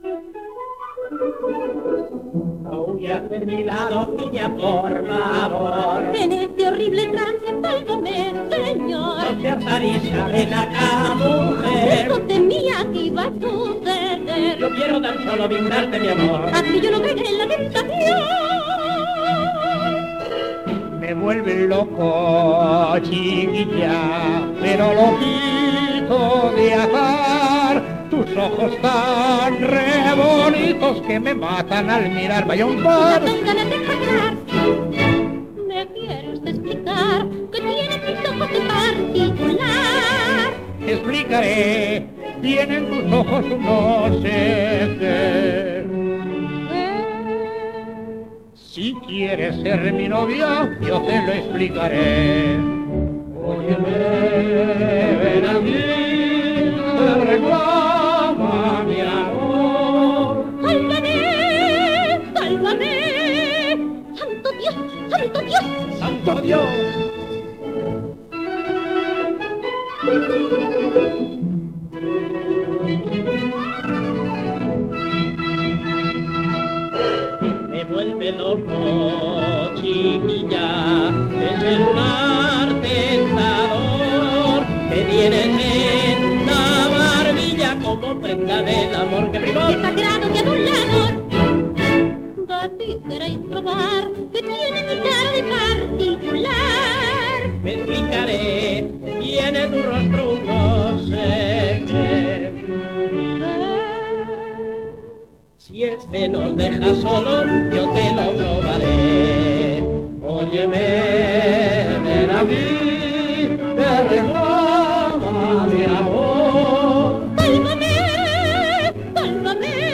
Terceto cómico.
78 rpm